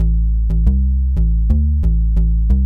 描述：柔和的弹拨式低音线在果味循环中制作，如果你使用它，请告诉我。
Tag: 90 bpm Chill Out Loops Bass Loops 459.55 KB wav Key : A